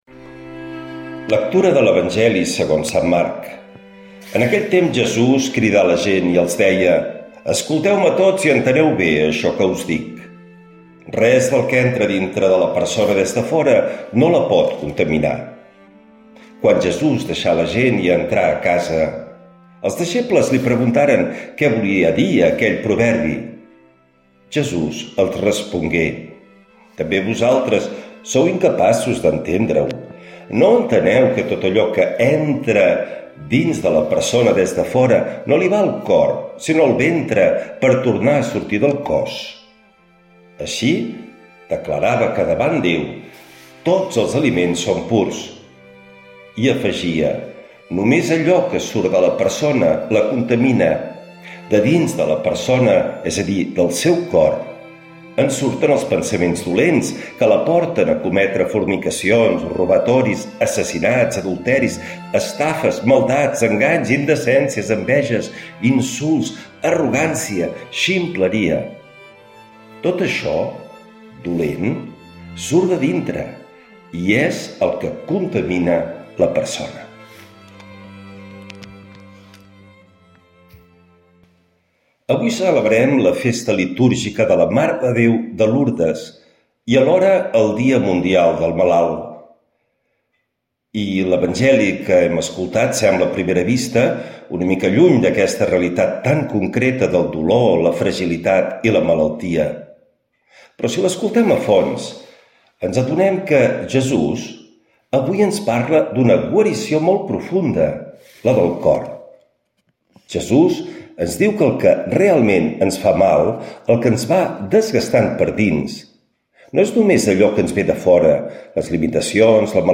L’Evangeli i el comentari de dimecres 11 de febrer del 2026.